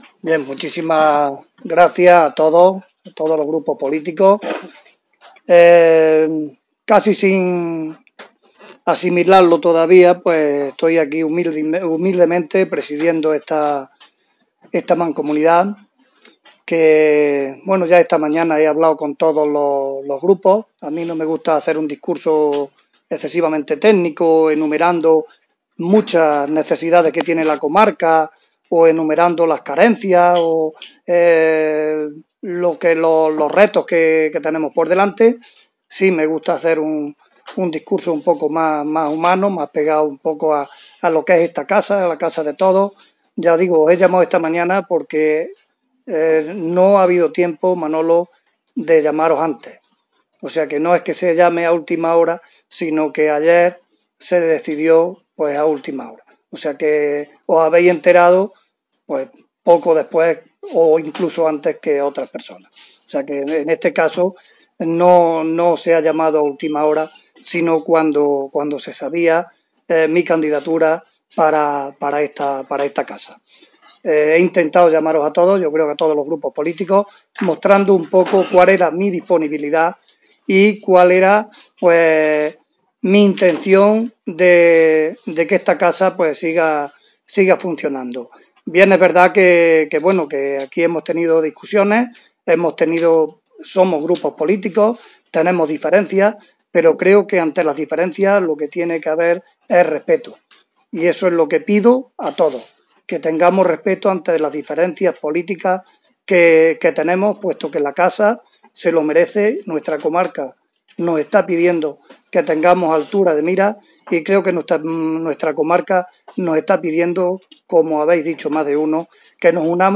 Escuchamos a Santiago Ruiz tras ser elegido: